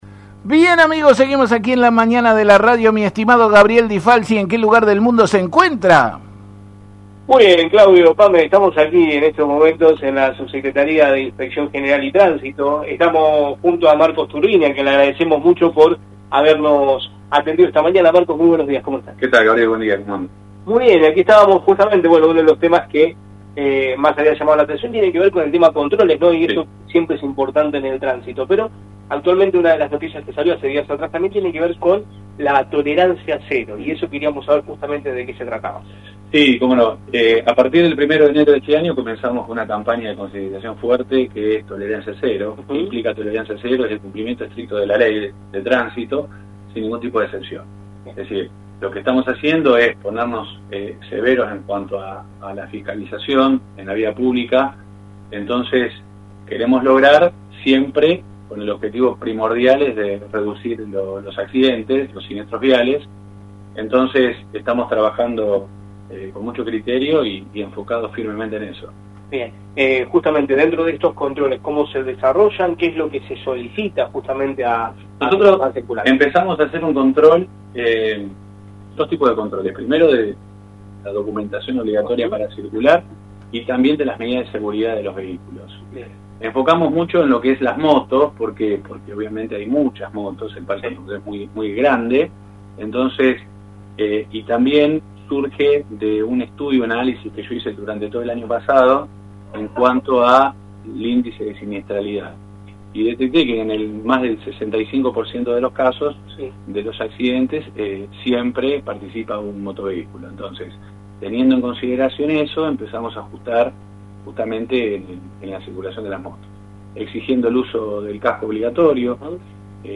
Desde el móvil de LT35 Radio Mon Pergamino AM 1540
entrevista